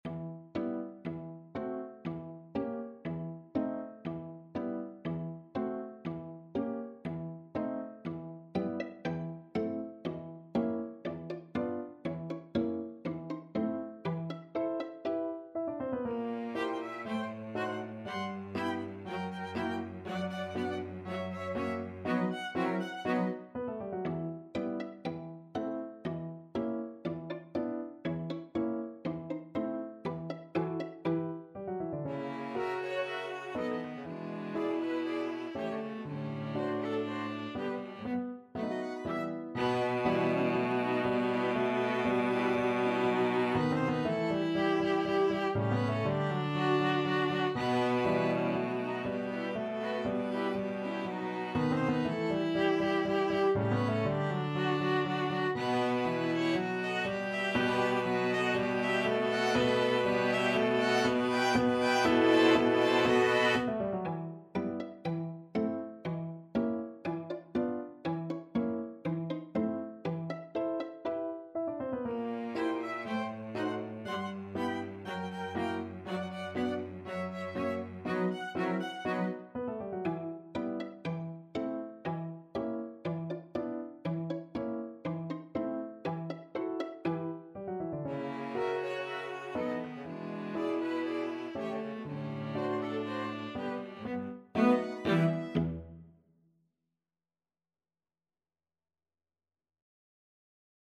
Violin 1Violin 2ViolaCelloPiano
E minor (Sounding Pitch) (View more E minor Music for Piano Quintet )
4/4 (View more 4/4 Music)
= 120 Fairly slow and graceful
Piano Quintet  (View more Intermediate Piano Quintet Music)
Classical (View more Classical Piano Quintet Music)